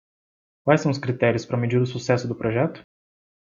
Read more to measure, gauge Frequency C1 Pronounced as (IPA) /meˈd͡ʒi(ʁ)/ Etymology Inherited from Latin metior In summary From Old Galician-Portuguese medir, from Latin mētīrī, from Proto-Indo-European *meh₁- (“to measure”).